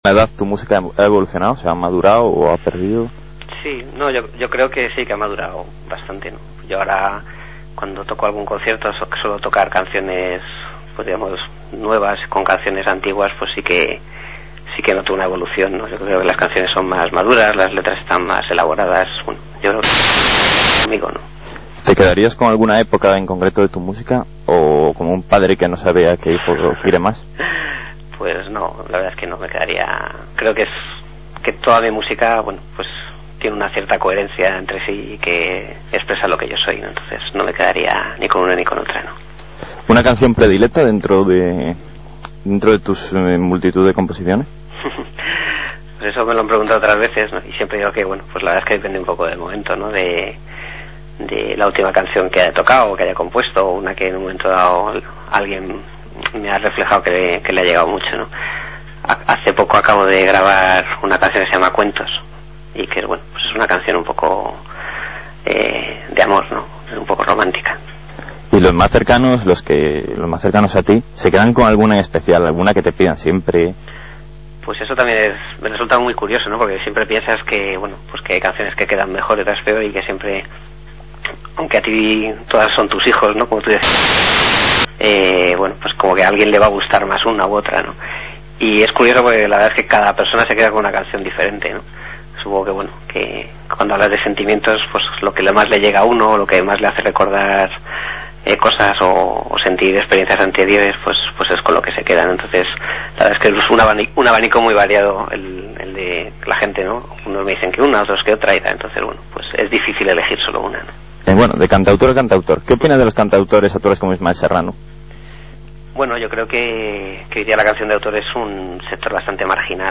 ENTREVISTA EN "EL RITMO DEL GARAJE" DE VILLAVICIOSA RADIO
Esta es la entrevista que me hicieron en el programa "El ritmo del garaje" de Villaviciosa Radio (107.5 FM) y que se emitió el pasado 19 de Abril de 2005.
3.- Segunda parte de la entrevista (6' 34")